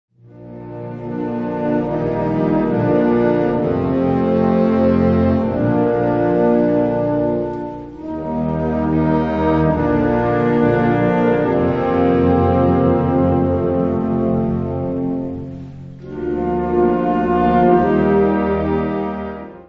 Categoría Banda sinfónica/brass band
Subcategoría Música de concierto / Música sinfónica
Instrumentación/orquestación Ha (banda de música)